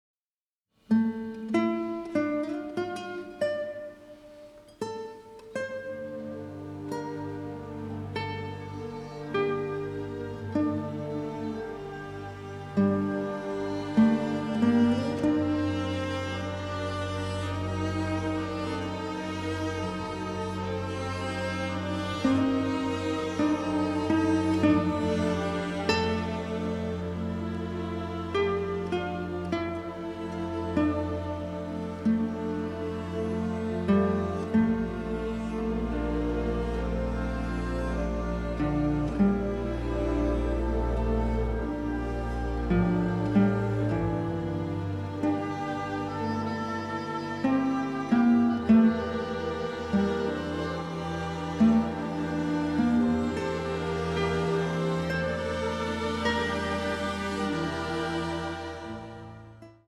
deeply delicate score